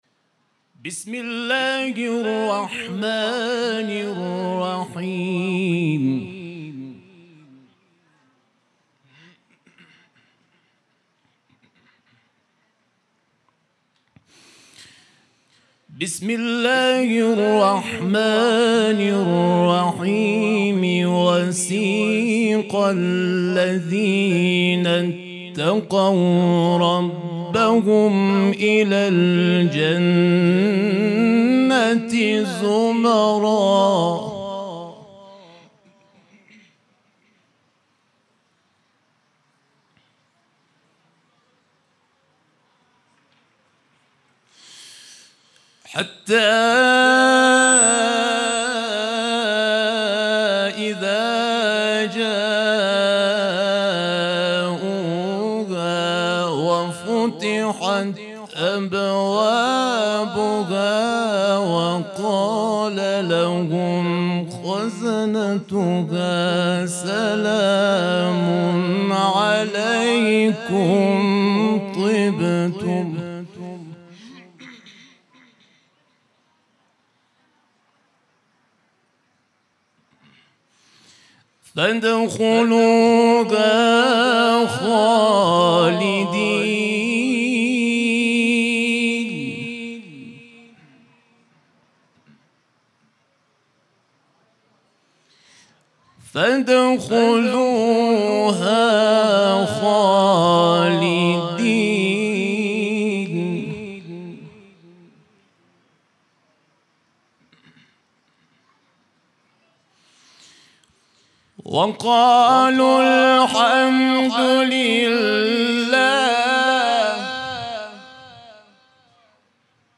برچسب ها: تلاوت قرآن ، صوت تلاوت ، قاری ممتاز کشوری